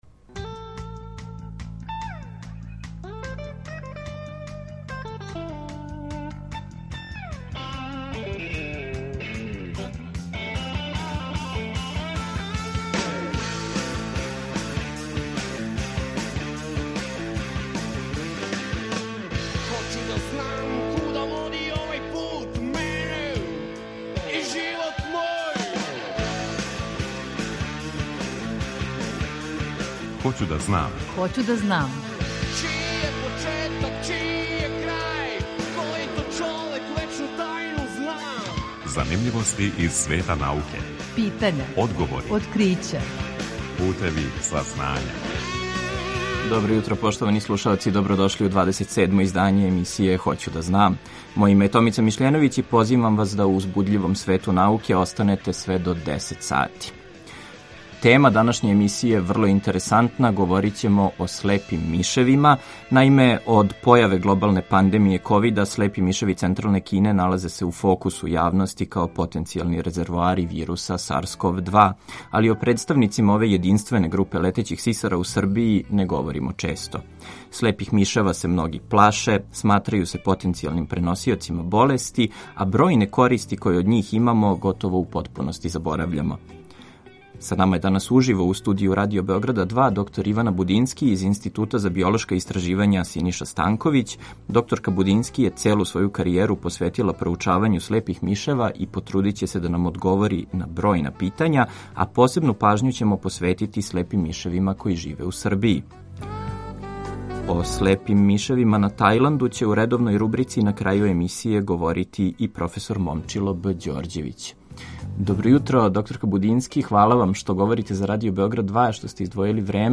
Eмисијa „Хоћу да знам“, посвећенa je популарној науци, која ће сваког четвртка од 9 до 10 сати, почев од 1. октобра 2020. доносити преглед вести и занимљивости из света науке, разговоре са истраживачима и одговоре на питања слушалаца.